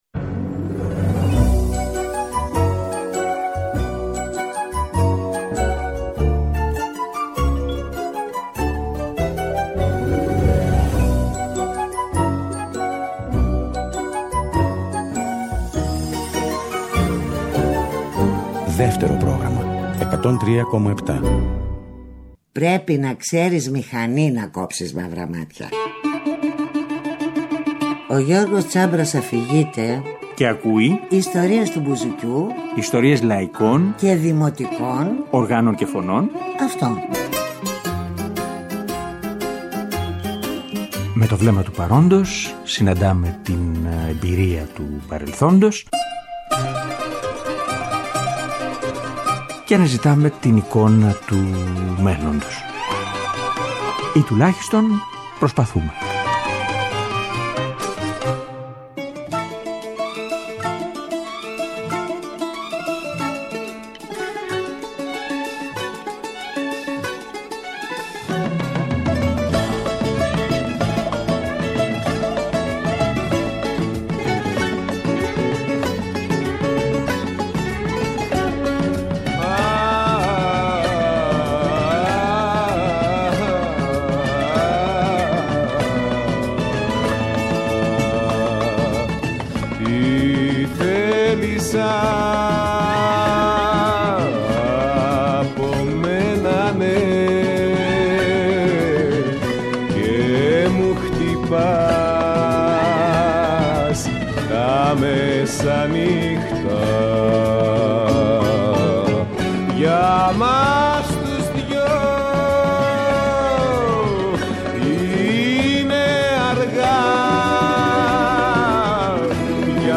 Ένα χρονικό πρώτων εκτελέσεων και επανεκτελέσεων, χωρίς πολλά λόγια, με στόχο μια πρώτη γενική αίσθηση.